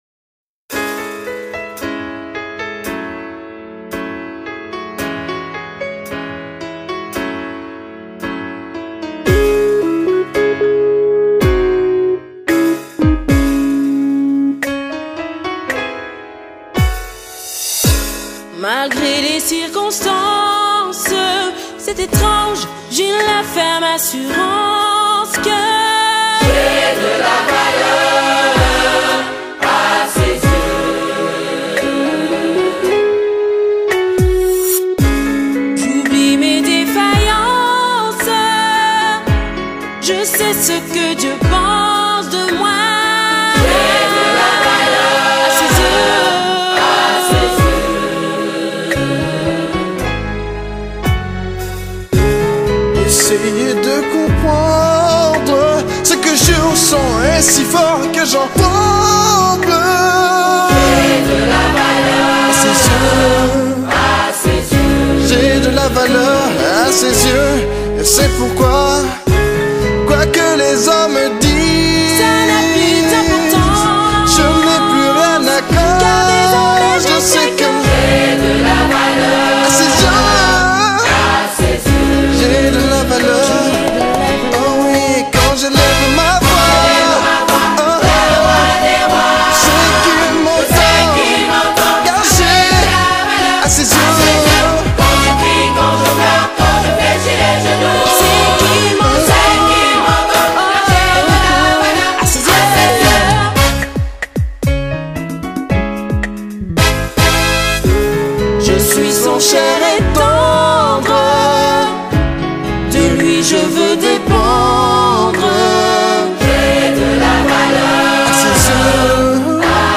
Chant-Lauréats-2017.mp3